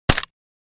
Download Free War Sound Effects | Gfx Sounds
AK-47-assault-rifle-suppressed-shot.mp3